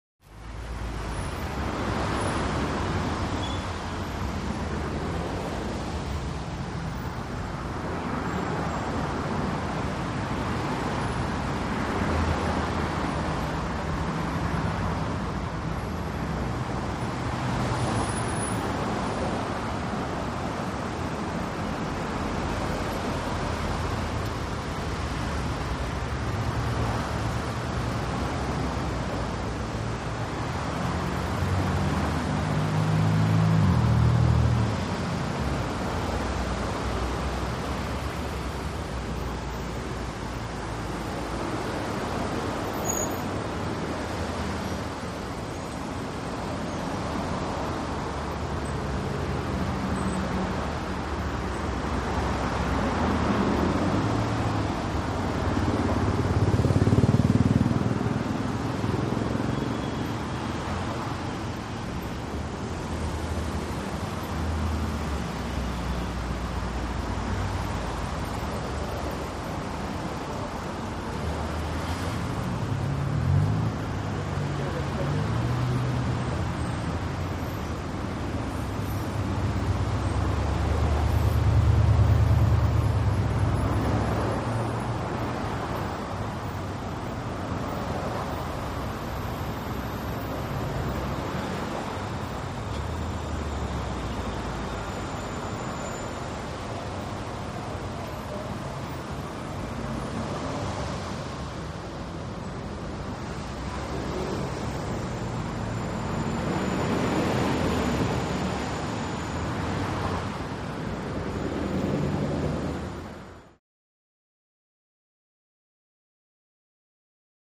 Heavy Traffic Bys On Bridge, Close. Middle Of Bridge Point Of View, Cars And Busses Whooshy Bys On Both Sides. Constant Noise With Defined Bys, Brake Squeaks.